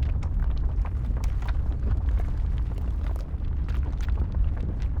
Nature Cast Loop 1.wav